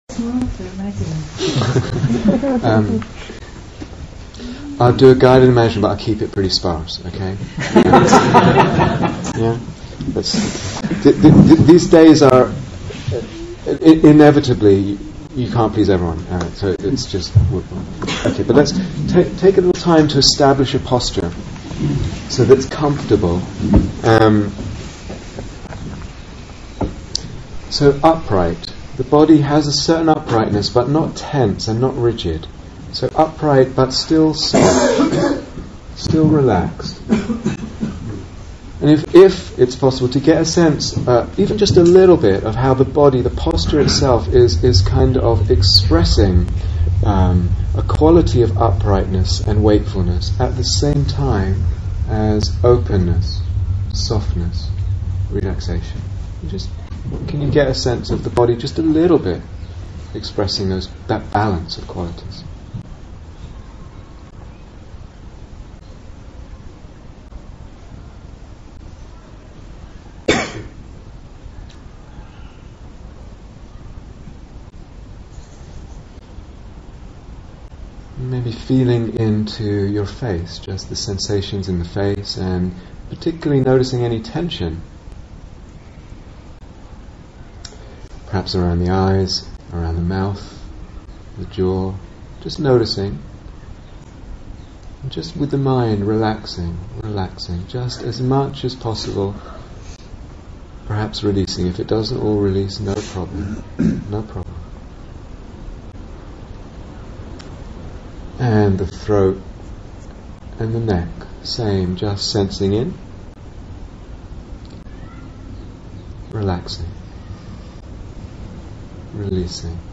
Guided Meditation 1
Guided Meditation 1 Download 0:00:00 25:40 Date 7th December 2014 Retreat/Series Day Retreat, London Insight 2014 Transcription I'll do a guided meditation, but I'll keep it pretty sparse, okay?